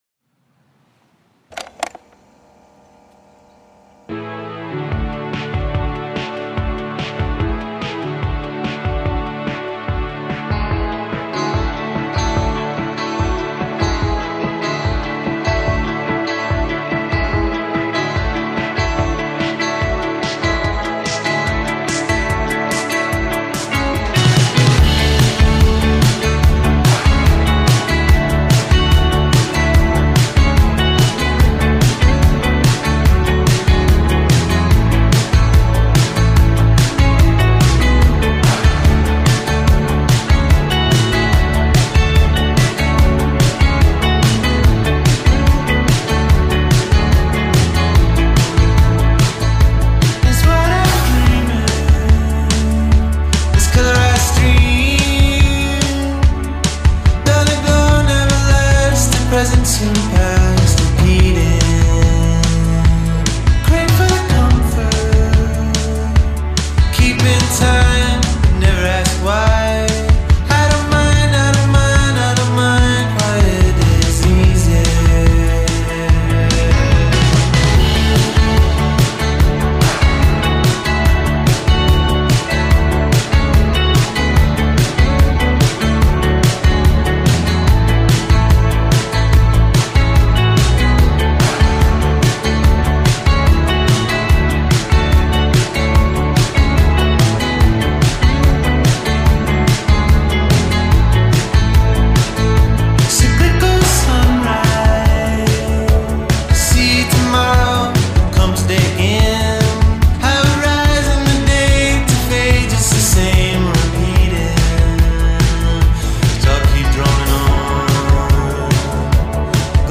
dream-pop